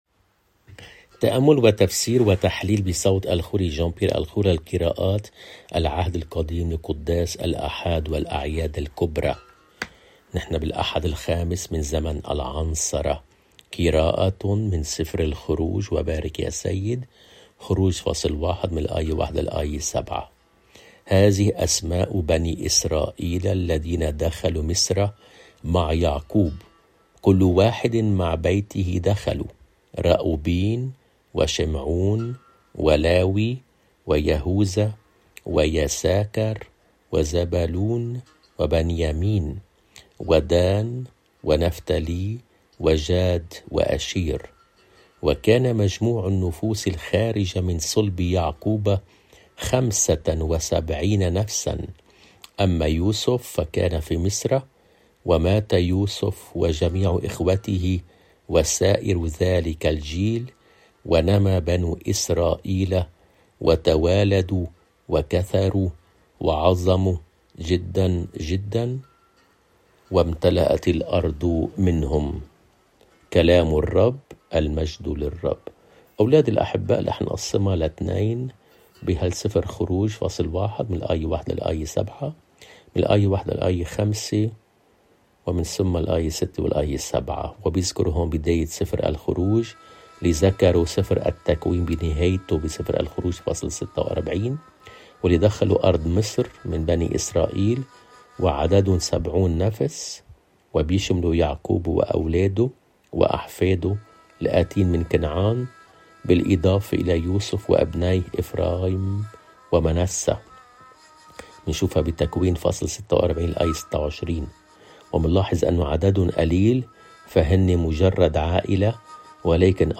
قراءة من العهد القديم